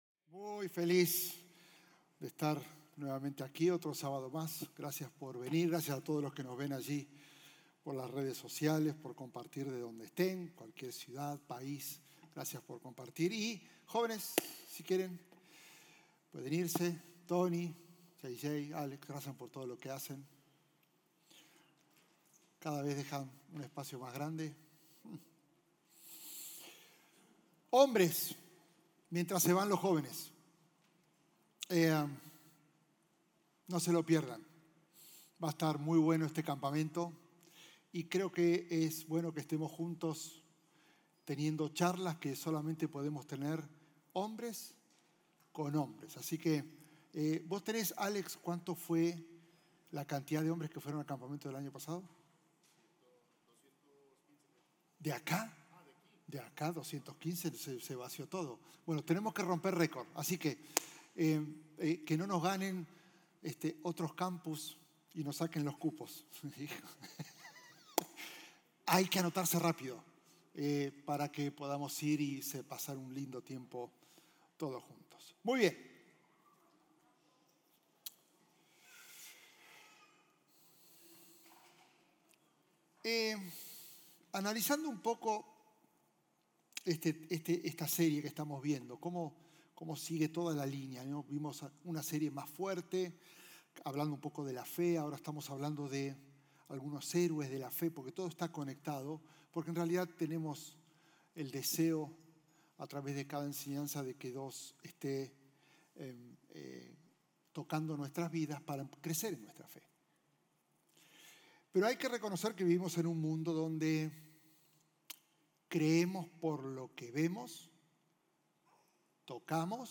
Un mensaje de la serie "Evidencias - JV ."